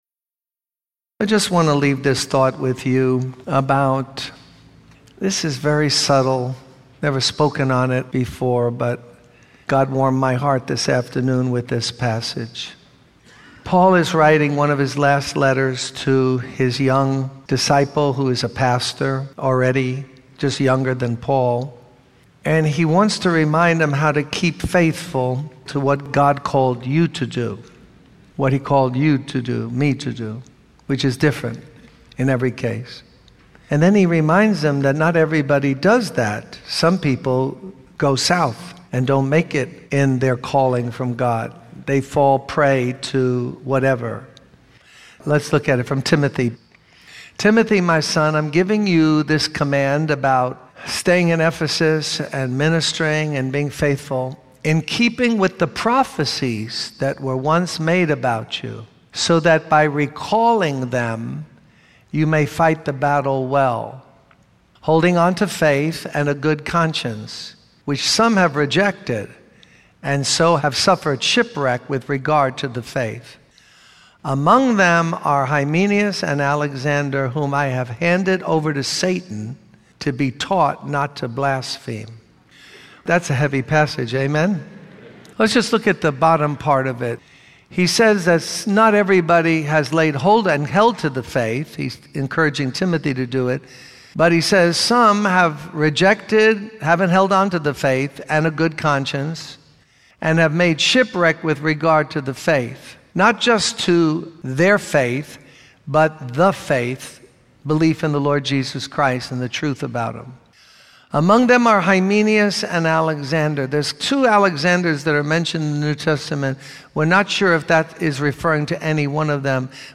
In this sermon, the preacher shares the story of a woman who was painfully shy and afraid to speak in public.